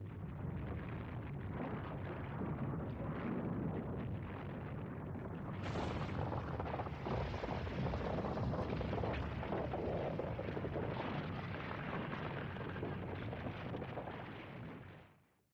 quake.opus